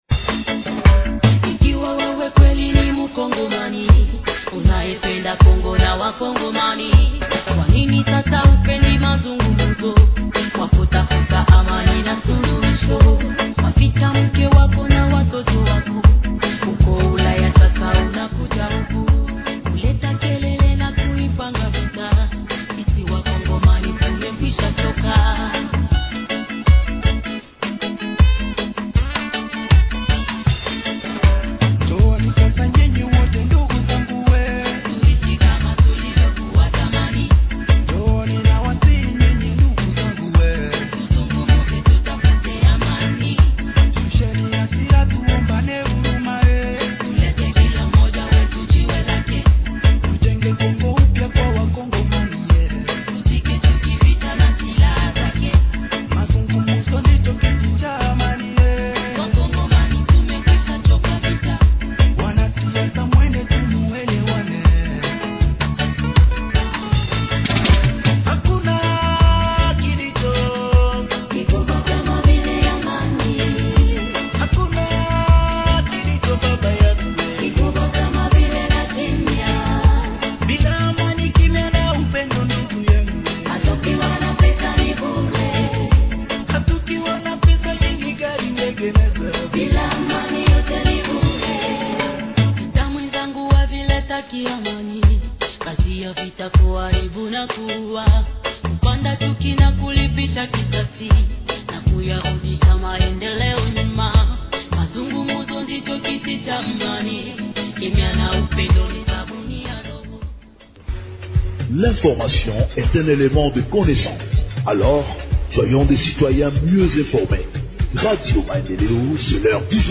Journal en Français du 22 Aout 2025 – Radio Maendeleo